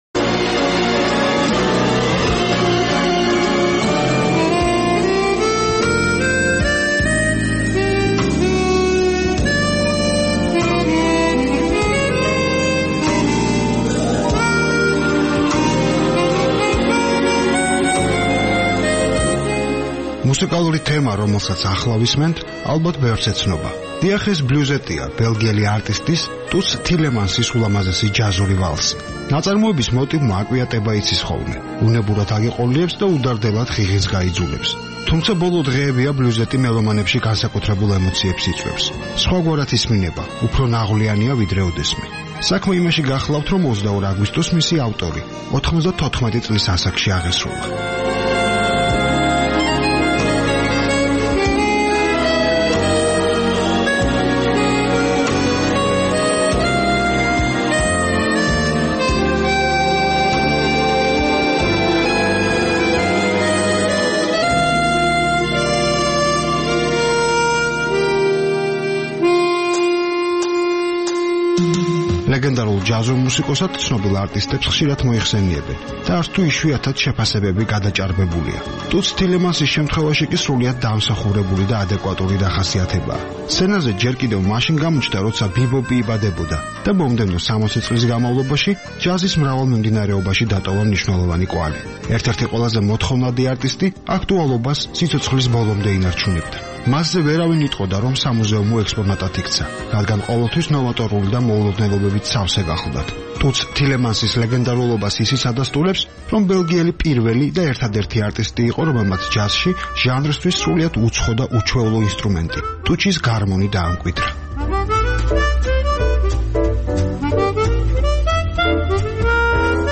მუსიკა, მუსიკა
მუსიკალური თემა, რომელსაც ახლა ვისმენთ, ალბათ, ბევრს ეცნობა. დიახ, ეს "ბლუზეტია", ბელგიელი არტისტის, ტუტს თილემანსის, ულამაზესი ჯაზური ვალსი.